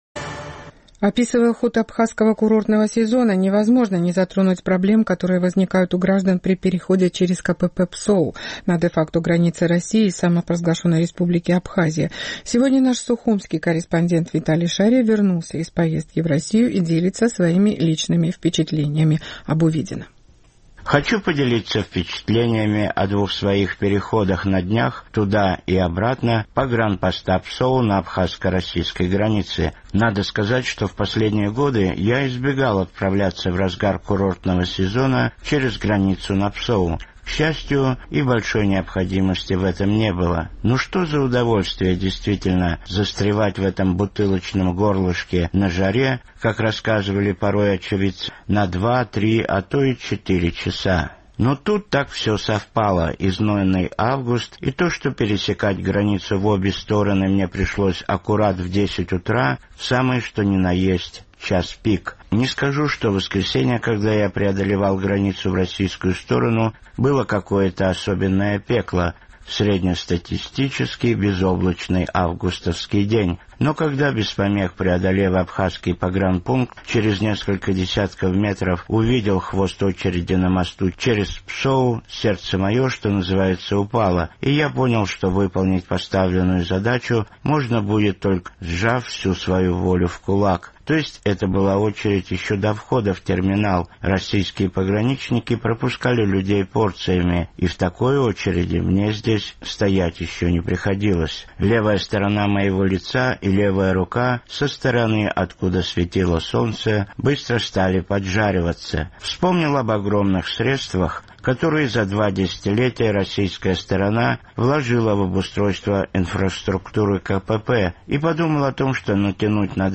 Репортаж из «газовой камеры»